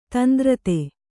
♪ tandrate